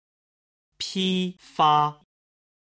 今日の振り返り！中国語発声